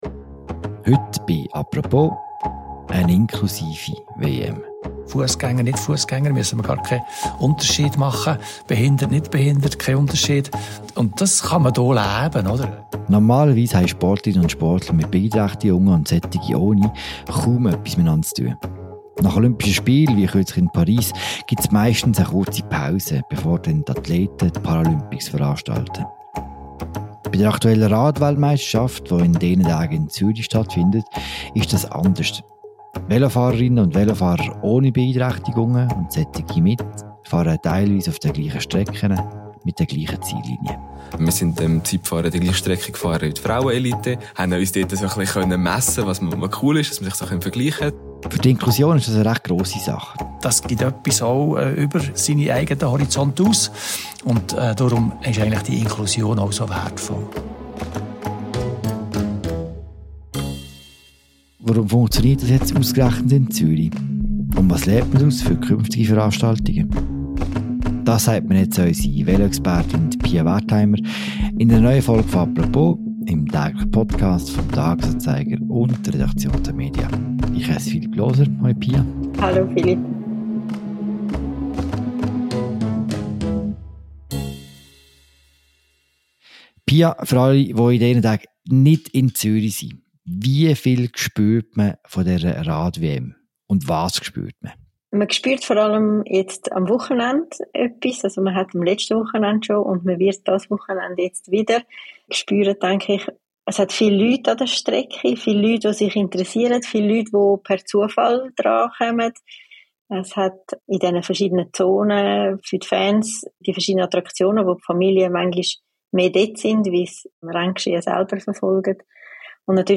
Zudem kommen zwei Radathleten aus verschiedenen Generationen zu Wort